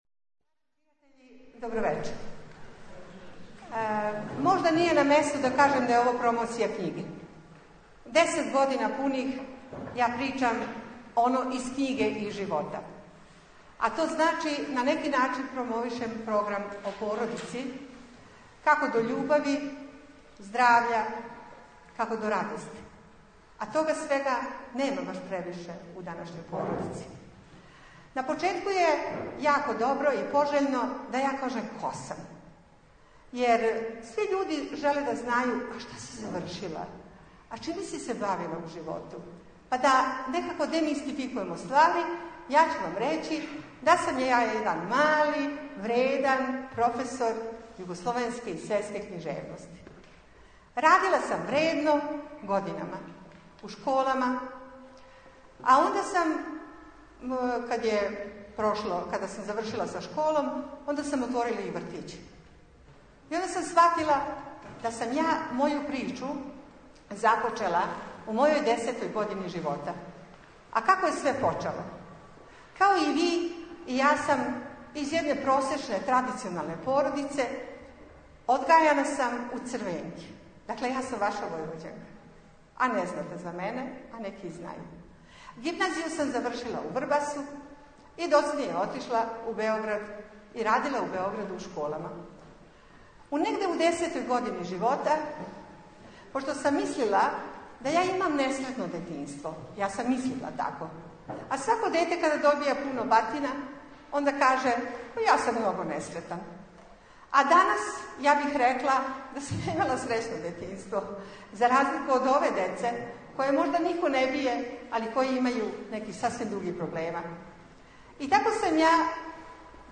Звучни запис предавања